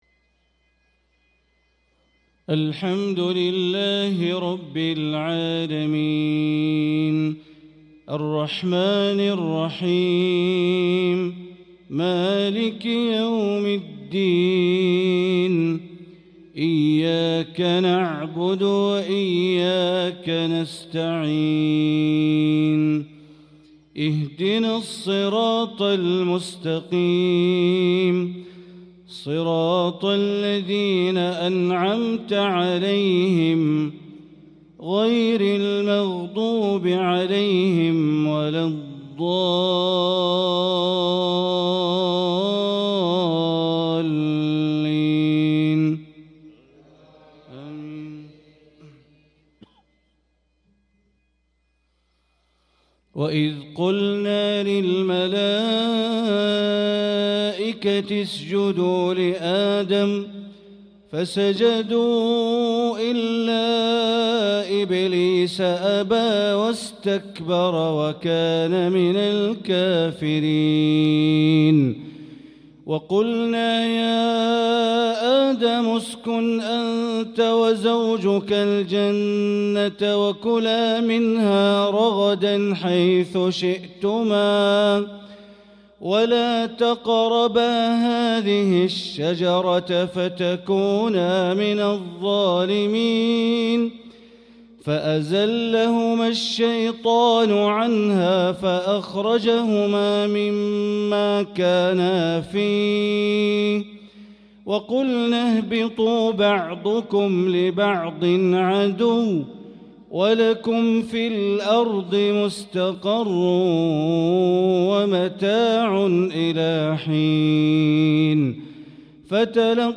صلاة العشاء للقارئ بندر بليلة 23 رجب 1445 هـ